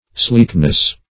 Sleekness \Sleek"ness\, n.